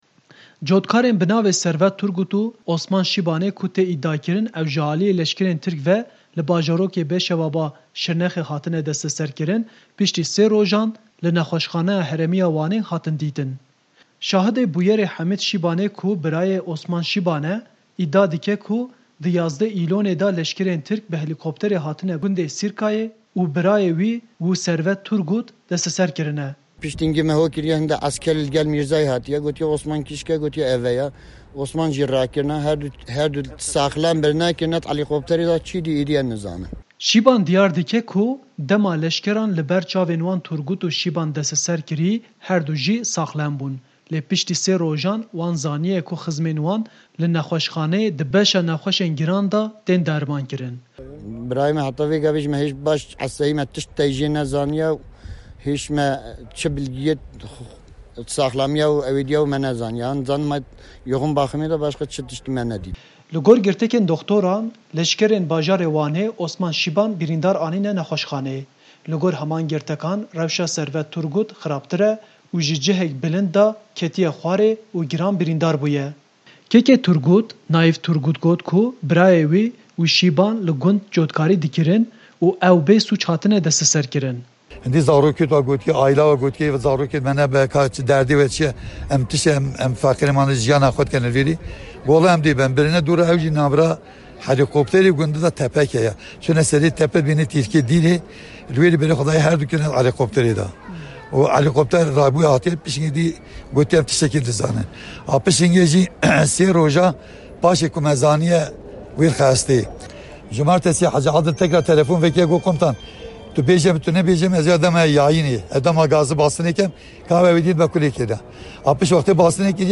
by دەنگی ئەمەریکا | Dengî Amerîka | VOA Kurdish